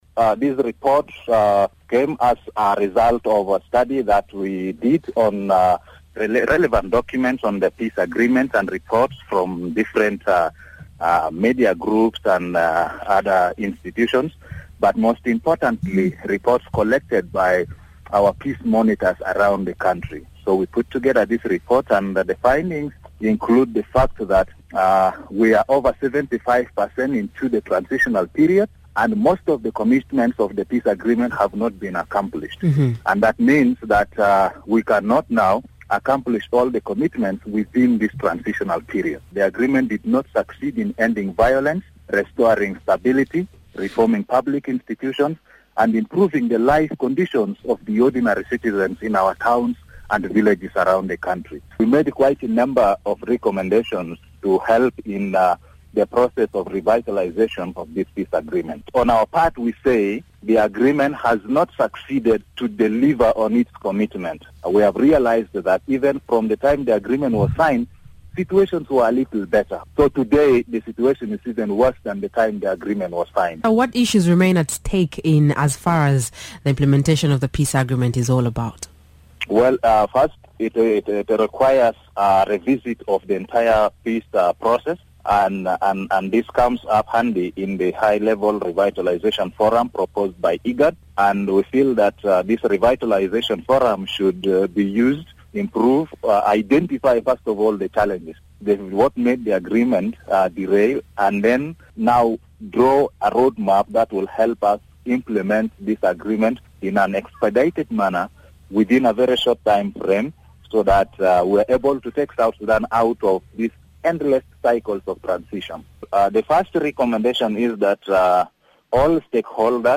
He spoke to the Miraya Breakfast show